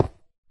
Minecraft / dig / stone1.ogg
stone1.ogg